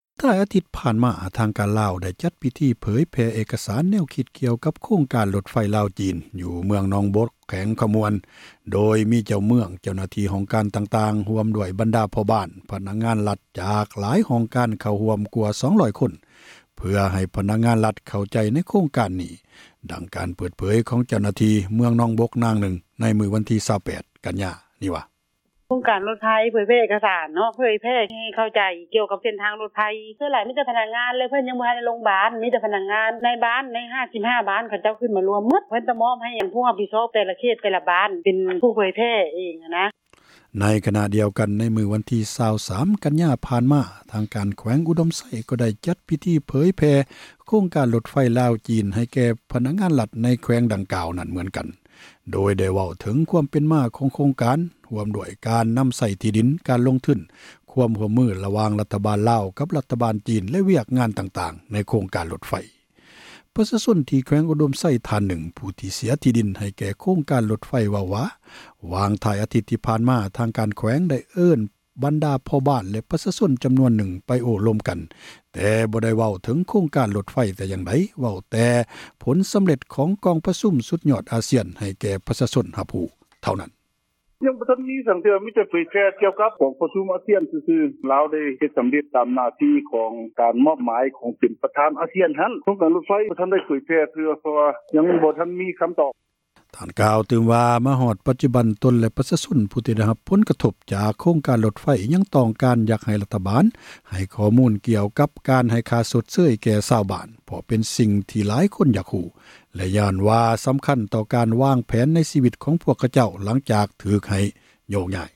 ດັ່ງ ການເປີດເຜີຍ ຂອງ ເຈົ້າຫນ້າທີ່ ເມືອງໜອງບົກ ນາງນຶ່ງ ໃນວັນທີ 28 ກັນຍາ ນີ້ວ່າ: